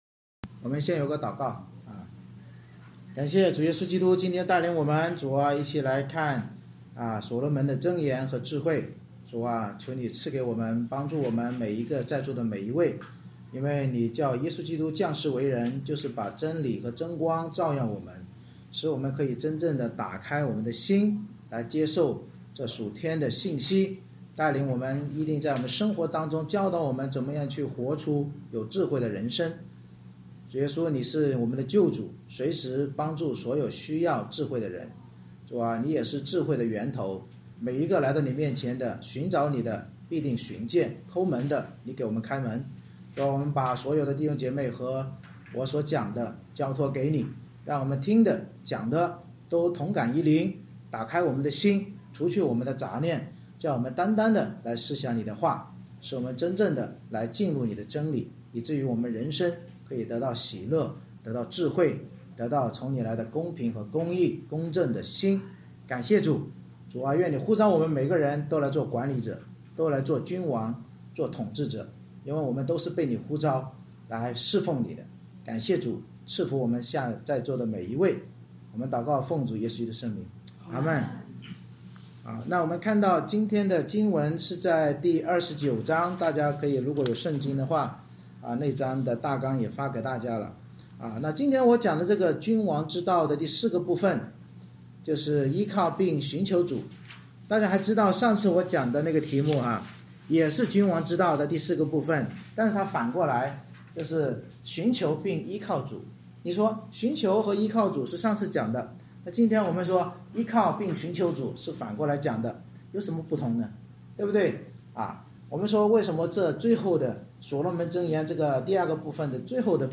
《箴言》讲道系列 Passage: 箴言29章 Service Type: 主日崇拜 通过希西家的人所誊录所罗门箴言，教导我们要成为公平而有异象的君王或管理者，就不要怕人求人而要依靠寻求主才能赢得将来公义审判。